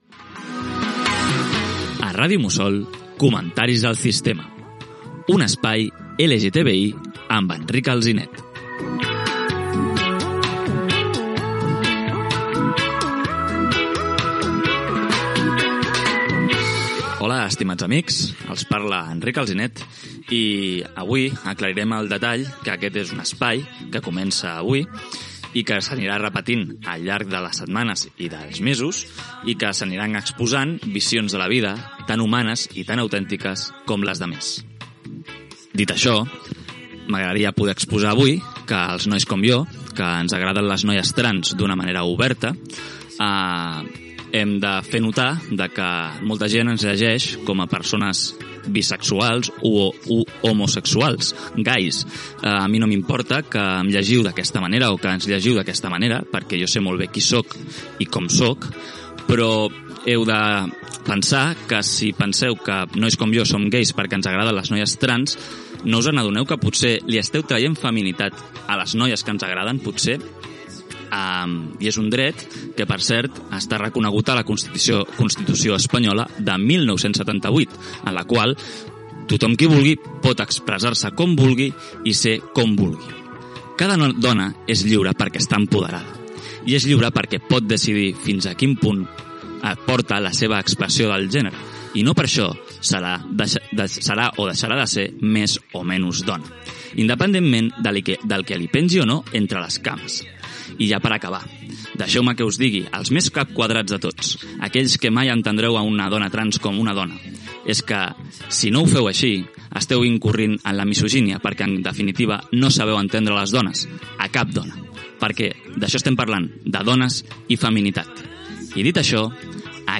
Identificació del programa i de l'emissora. Comentari sobre el drets, realitats i percepcions del col·lectiu de persones transsexuals.
Divulgació